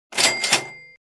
Money Sound Button - Botón de Efecto Sonoro